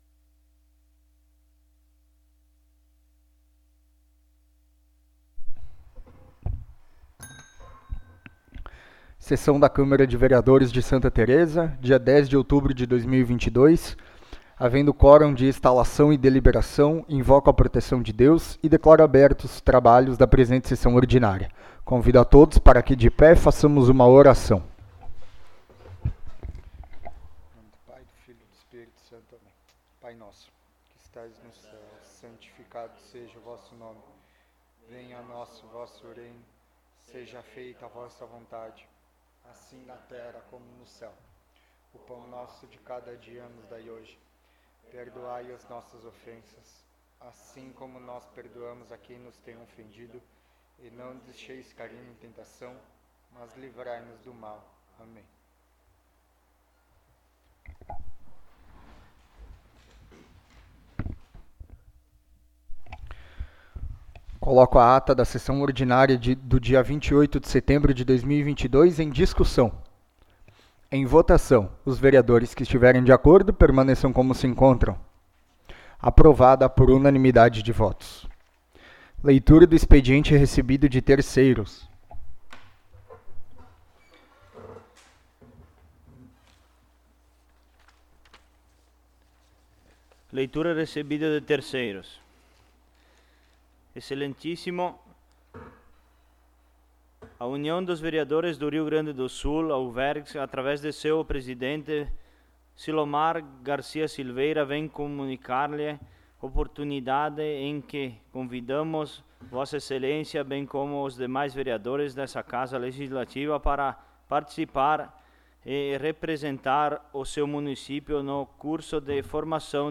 17ª Sessão Ordinária de 2022
Local: Plenário Pedro Parenti